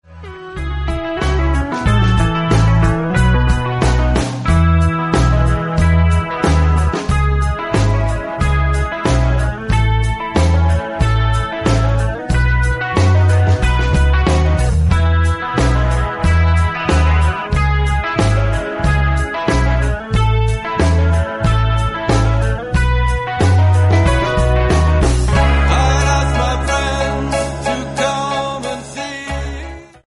MP3 – Original Key – Backing Vocals Like Original
Pop